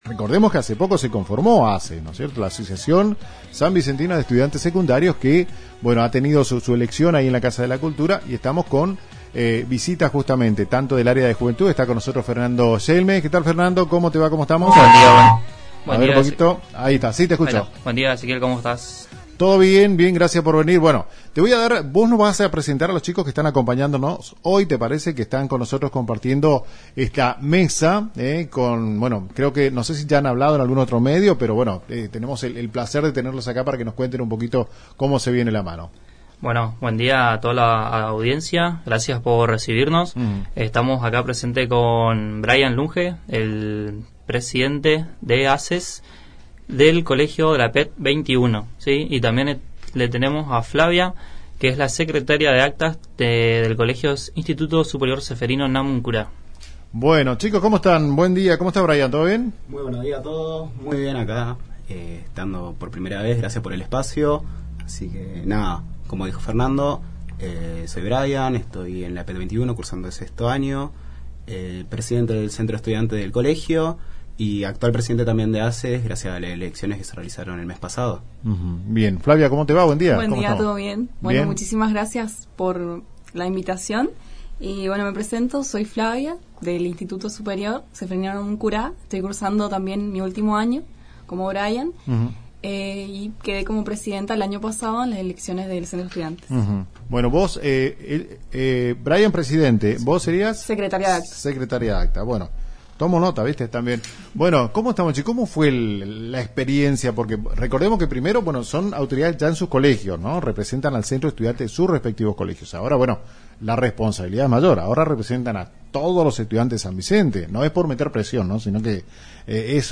ENTREVISTA-ASES.mp3